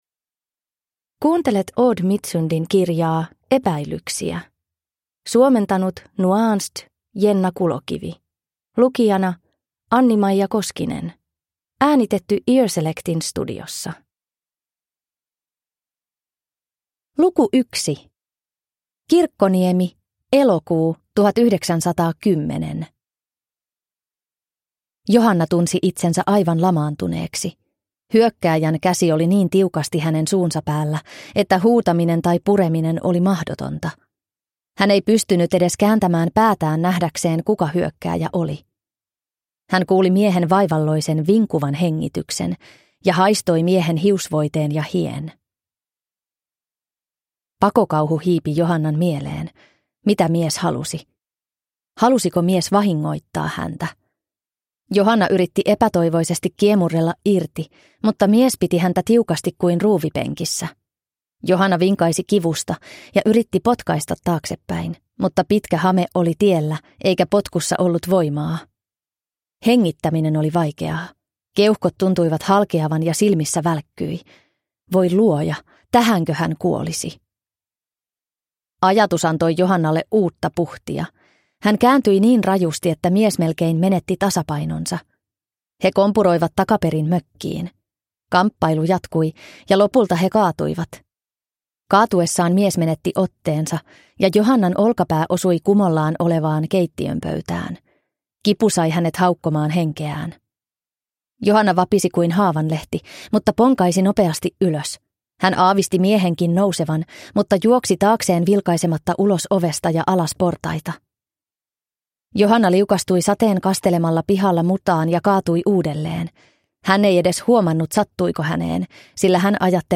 Epäilyksiä – Ljudbok